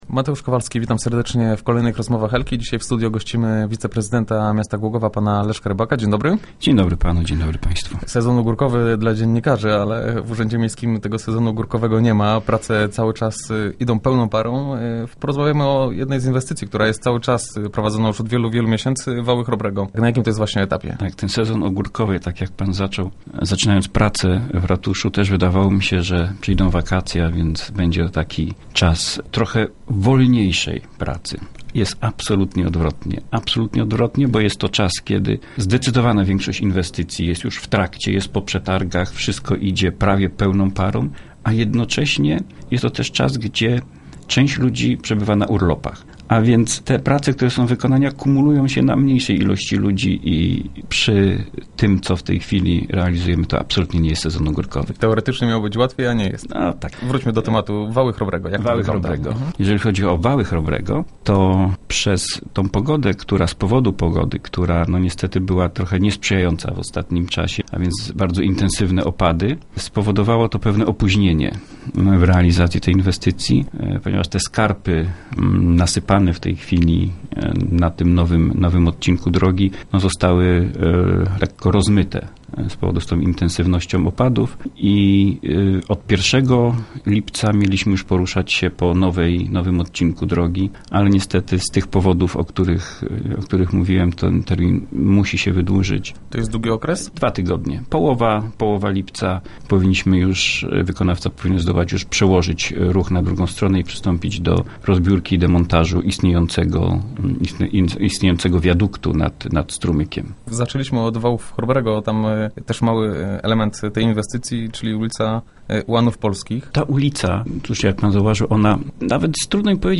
Trwa remont Wałów Chrobrego, przygotowywany jest projekt zagospodarowania portu kolegiackiego. – Jedni urzędnicy są na urlopach, drudzy niestety muszą pracować ze zdwojoną siłą – mówi wiceprezydent Leszek Rybak, który był gościem środowych Rozmów Elki.